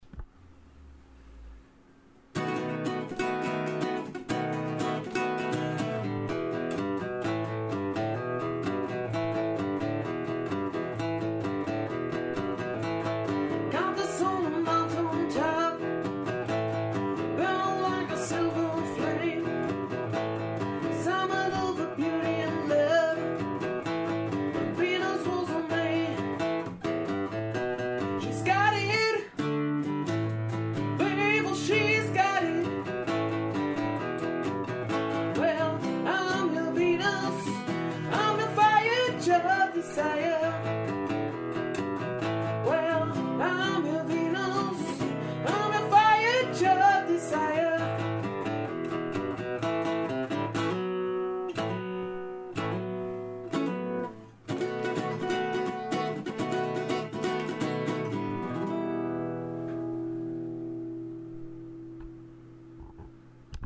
1 Stimme, eine Gitarre
schöner Sound im kleinen Rahmen
• Unplugged